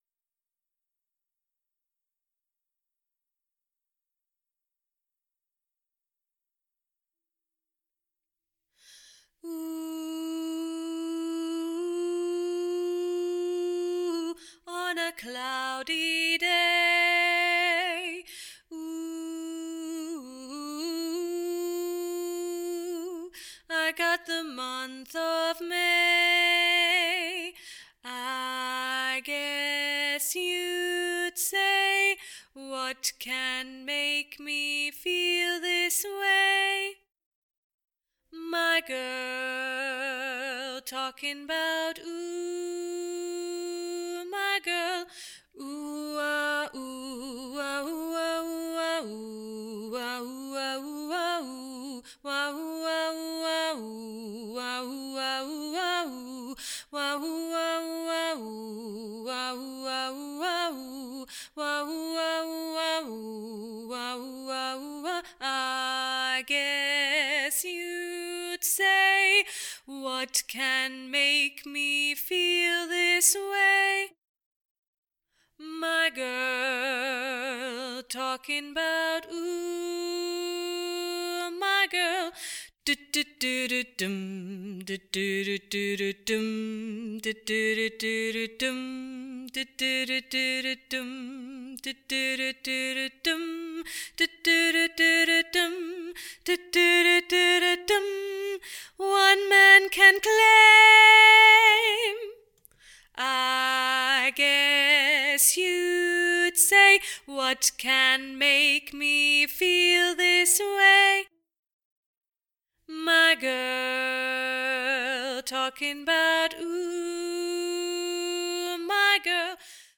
My Girl Alto
My-Girl-Alto.mp3